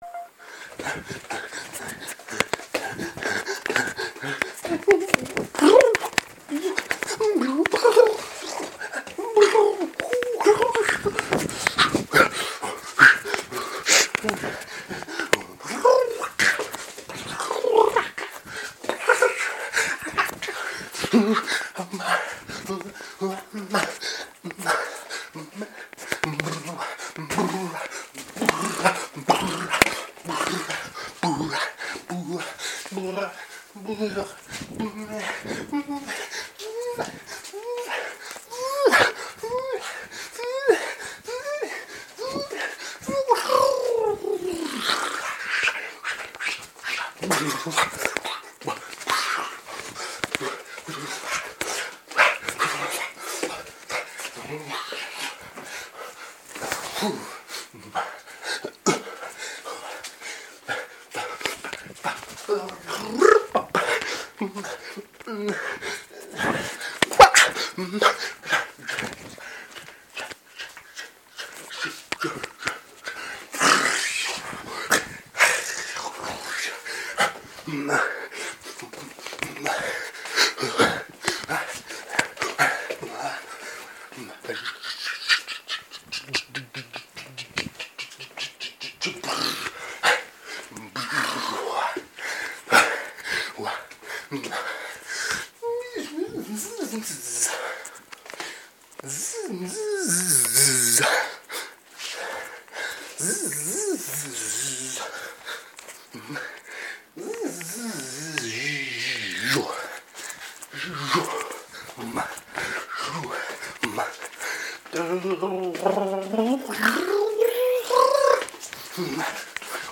Vocal dancing improv
Today's audio for 28, vocal improv created during improvised dance.